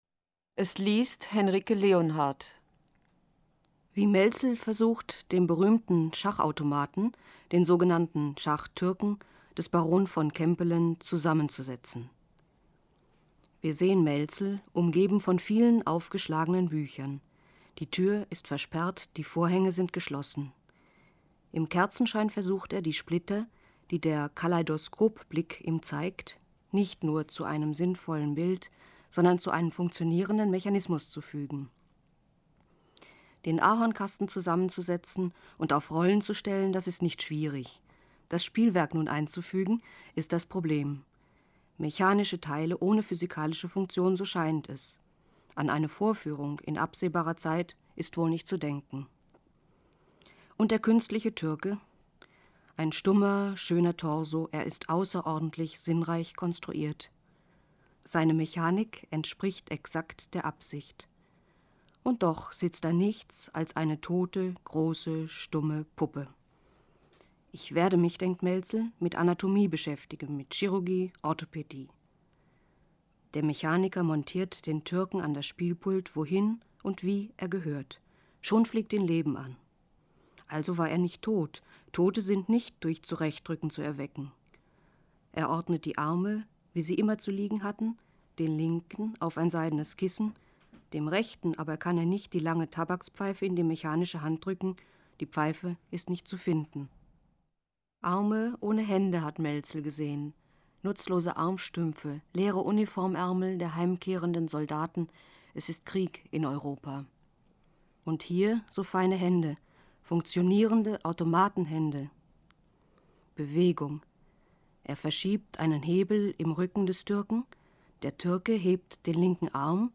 Das Literaturtelefon-Archiv wird in der Monacensia im Hildebrandhaus aufbewahrt. Es umfasst 40 CDs, auf denen insgesamt 573 Lesungen enthalten sind.